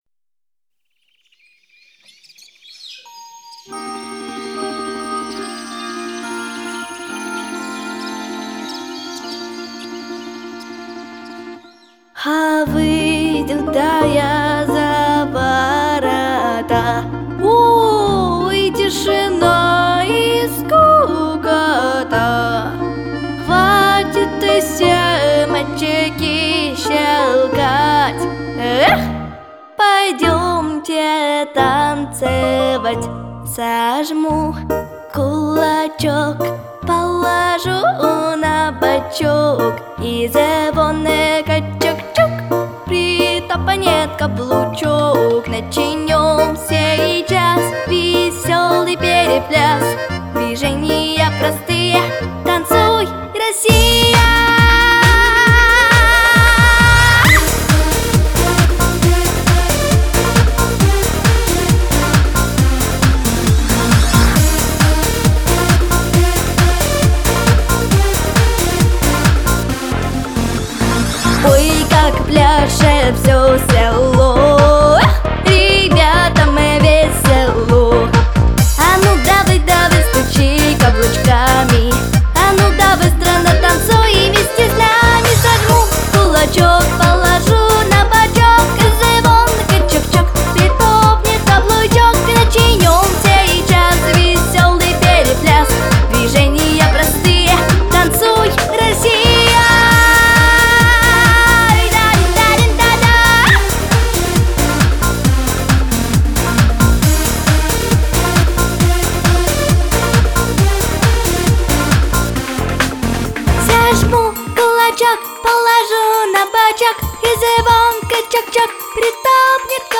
• Качество: Хорошее
• Категория: Детские песни
танцевальная